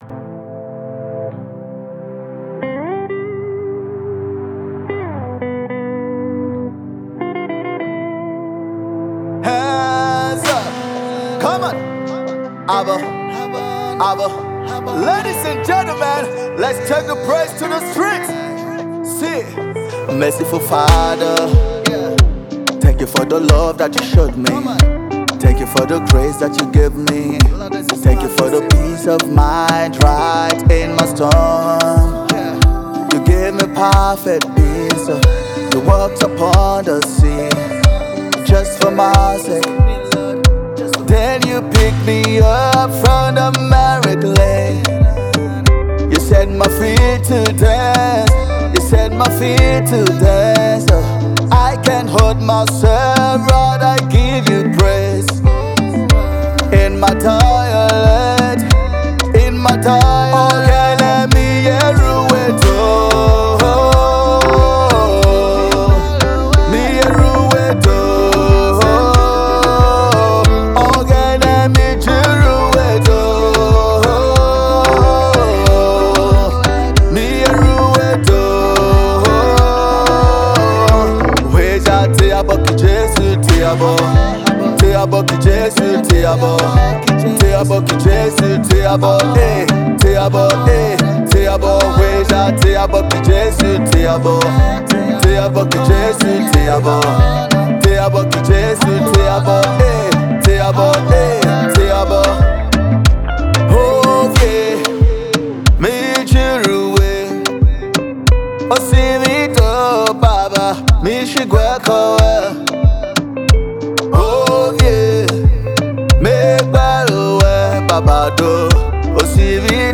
song of thanksgiving
timeless, sempiternal cheerful tune